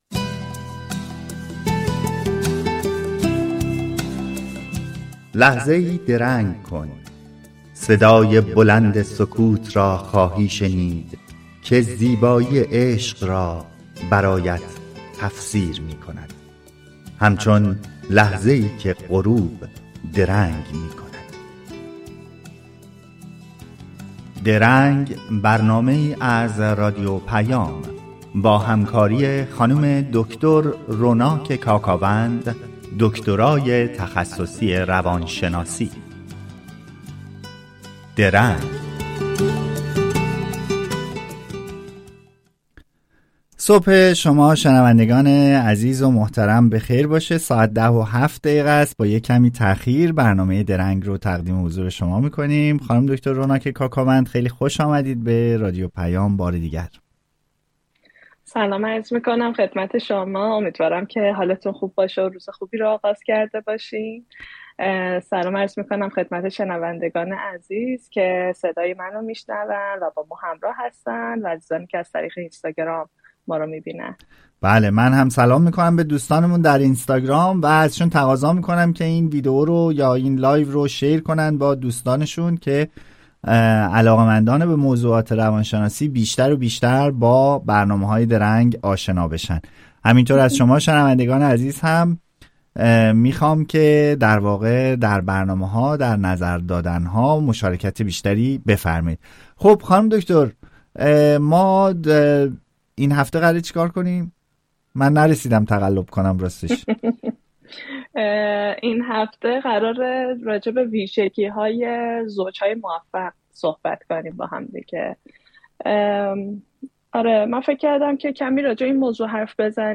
شما در این صفحه می‌توانید به برنامهٔ «درنگ» که هر هفته به‌صورت زنده از رادیو پیام گوتنبرگ سوئد پخش می‌شود، گوش دهید. این برنامه با هدف پرداختن به موضوعات متنوع اجتماعی، روانشناختی و فرهنگی تهیه و ارائه می‌شود.